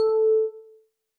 Techmino/media/sample/bell/13.ogg at 89134d4f076855d852182c1bc1f6da5e53f075a4